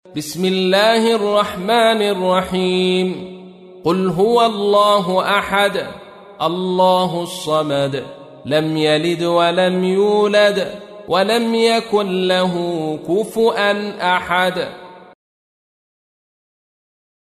تحميل : 112. سورة الإخلاص / القارئ عبد الرشيد صوفي / القرآن الكريم / موقع يا حسين